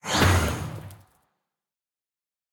Minecraft Version Minecraft Version 25w18a Latest Release | Latest Snapshot 25w18a / assets / minecraft / sounds / block / trial_spawner / spawn4.ogg Compare With Compare With Latest Release | Latest Snapshot